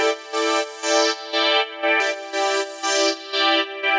Index of /musicradar/sidechained-samples/120bpm
GnS_Pad-MiscB1:4_120-E.wav